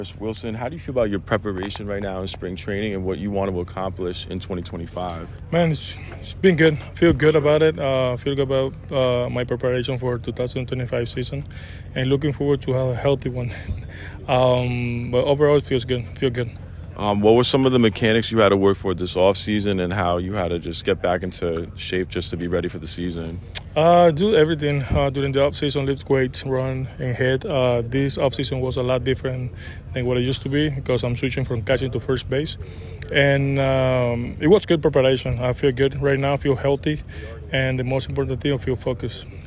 Contreras spoke before the Cardinals’ spring training game against the New York Mets at Roger Dean Chevrolet Stadium.
Wilson-Contreras-Interview_Conditioning.mp3